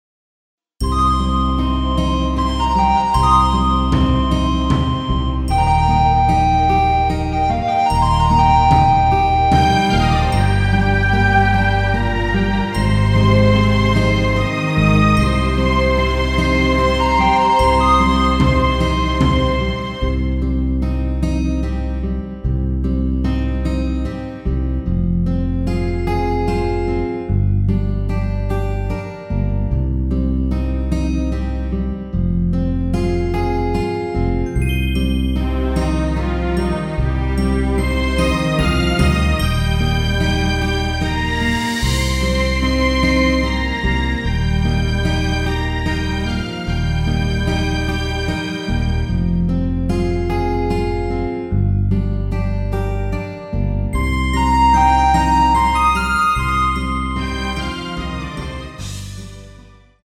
원키에서(+5)올린 MR입니다.
Cm
앞부분30초, 뒷부분30초씩 편집해서 올려 드리고 있습니다.